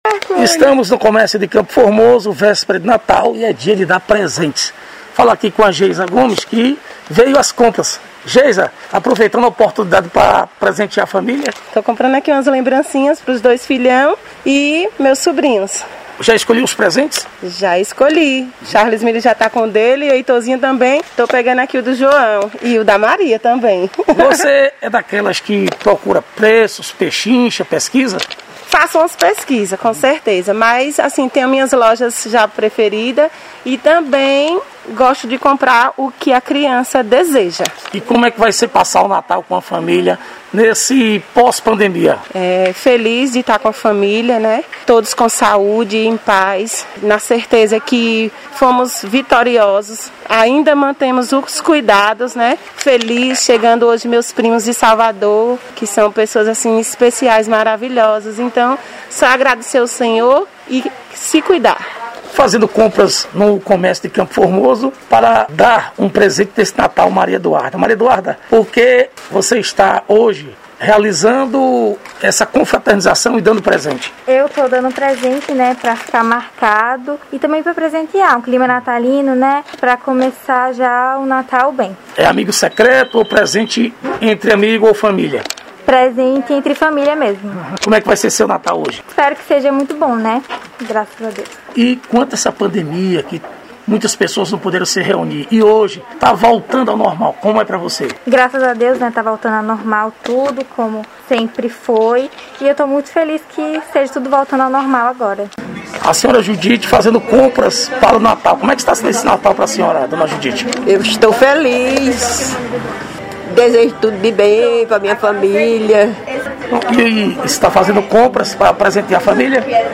Reportagem: Compras de Natal – Radio 98 FM